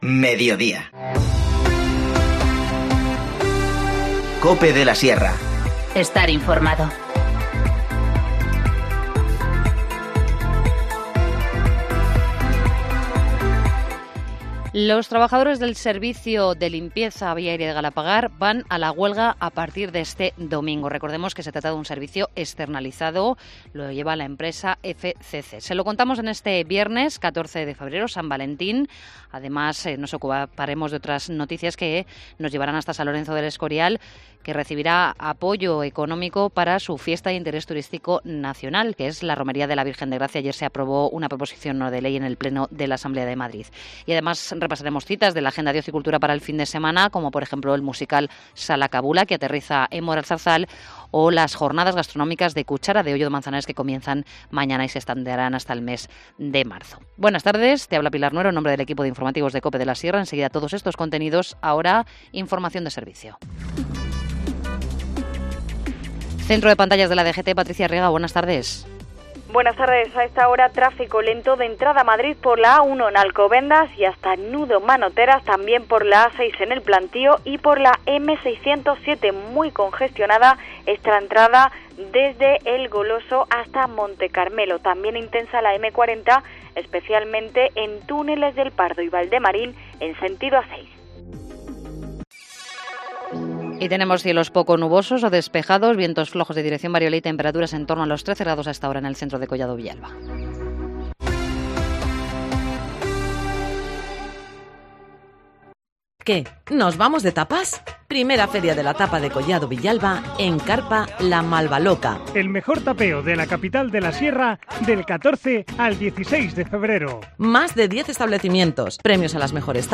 Informativo Mediodía 14 febrero 14:20h